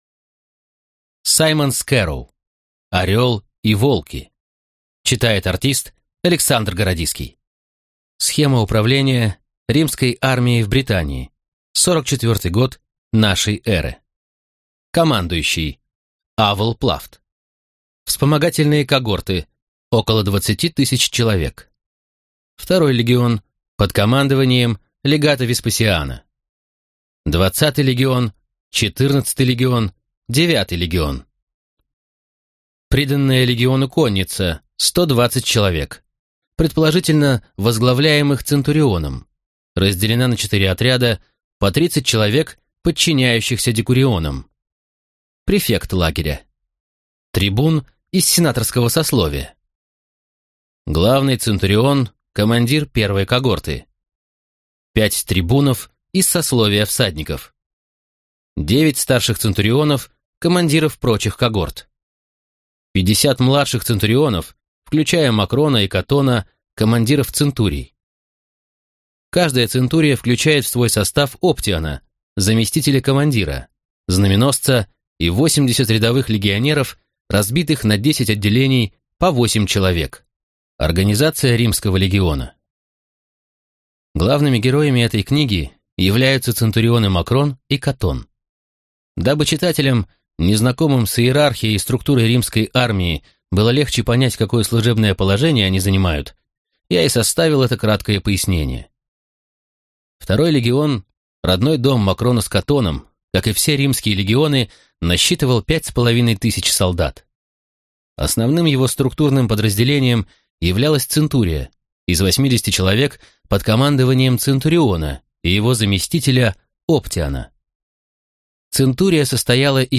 Аудиокнига Орел и волки | Библиотека аудиокниг